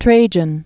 (trājən) Originally Marcus Ulpius Trajanus.